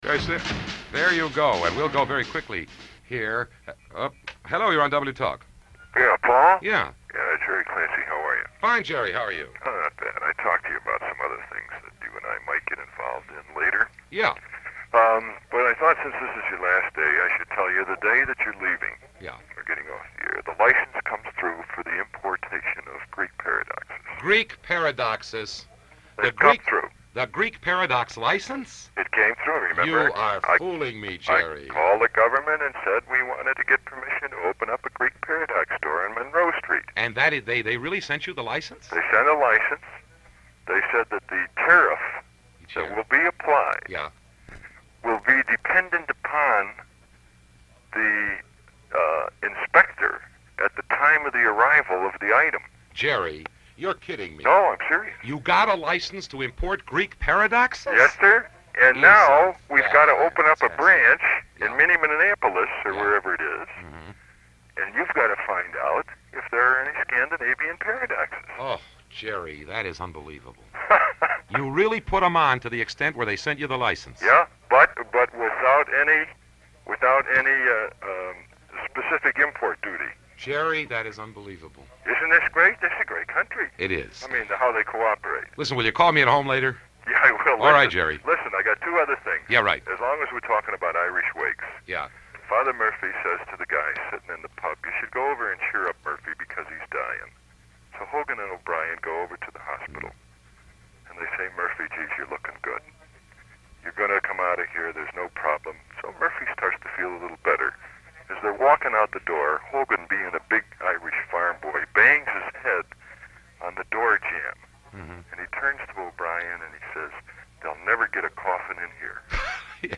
You will hear him talk and banter with familiar callers who read him poems and say goodbye, and he sings two songs that are very personal to him.